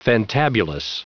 Prononciation du mot fantabulous en anglais (fichier audio)
Prononciation du mot : fantabulous